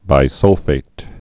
(bī-sŭlfāt)